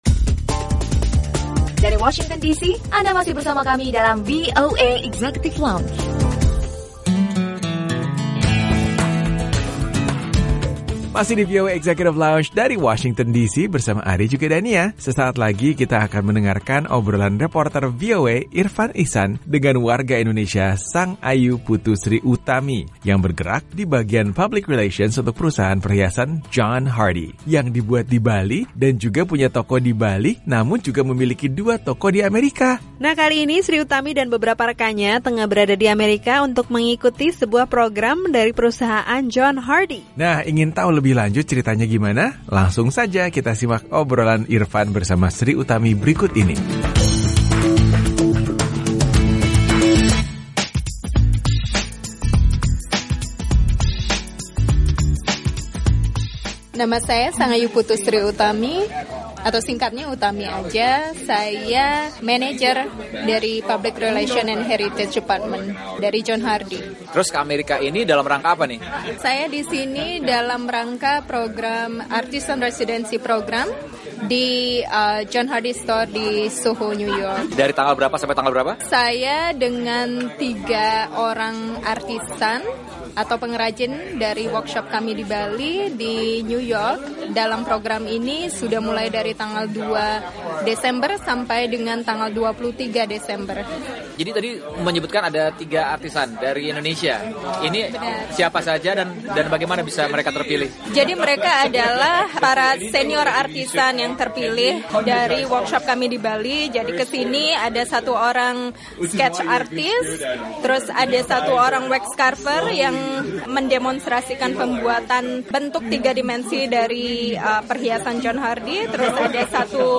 Obrolan VOA